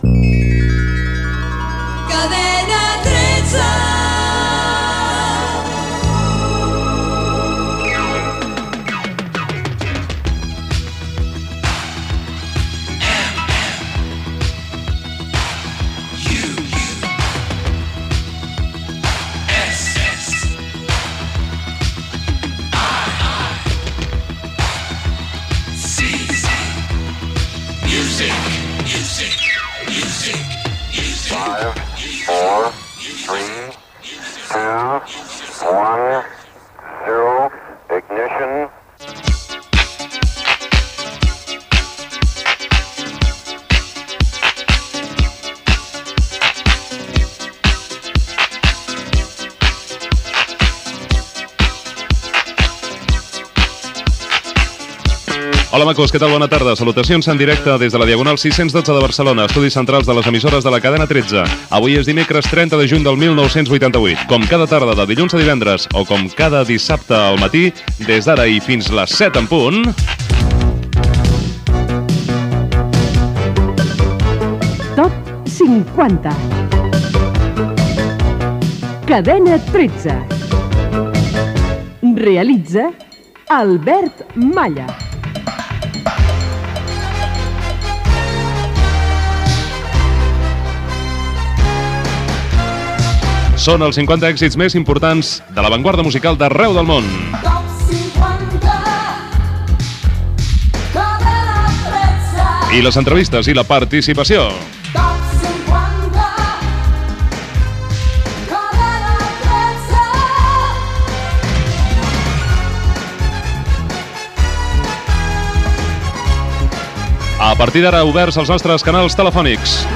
Indicatiu de la cadena, sintonia, inici del programa de llista d'èxits musicals, data, indicatiu del programa, presentació amb la invitació a la participació telefònica i esment dels corresponsals del programa i les freqüències de la Cadena 13.
Musical
FM